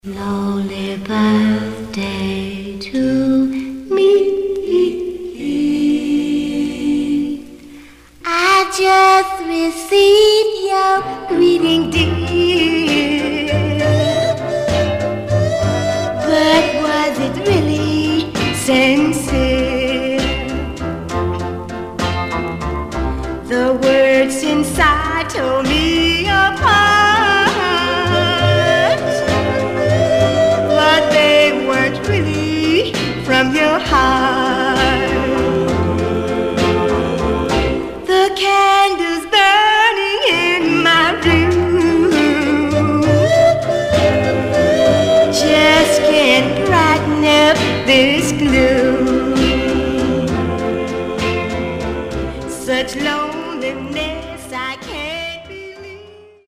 Stereo/mono Mono
Rockabilly